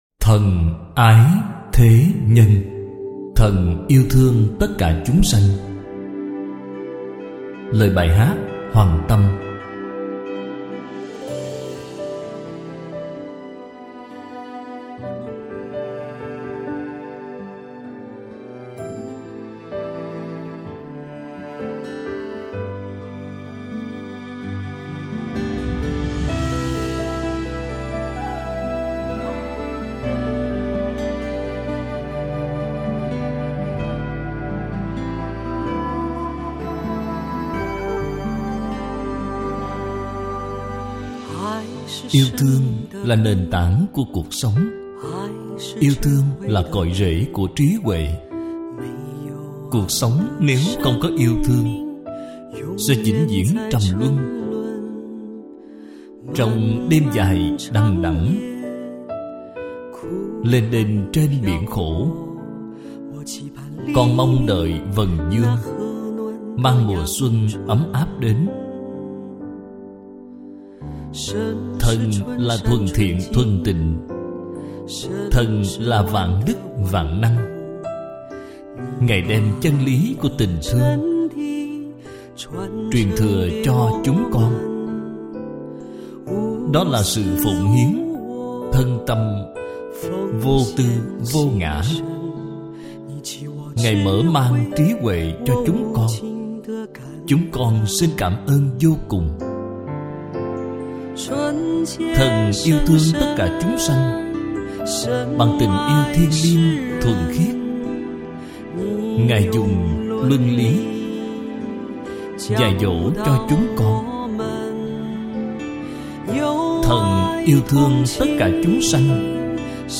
Bai Hat Than Ai The Nhan - hat tieng Hoa, doc tieng Viet.mp3